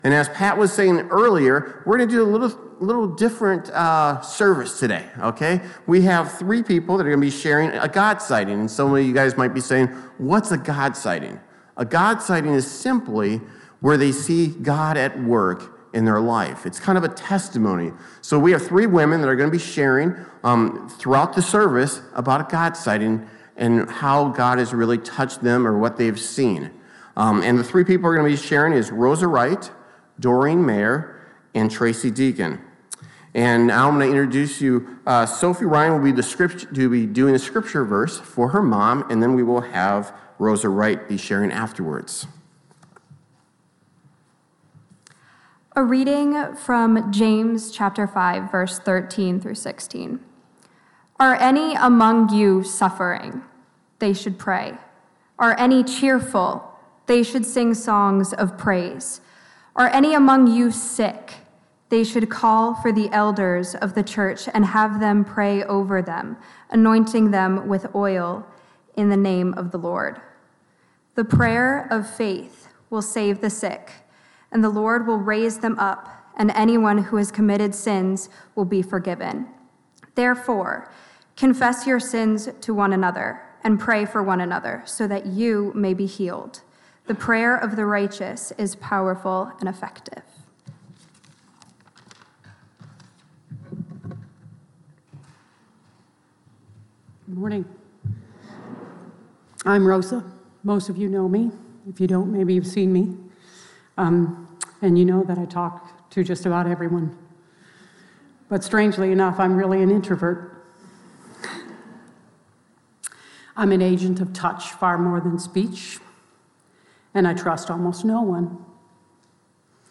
Members of our Brewster Baptist congregation share testimonies about how God has been at work in their lives.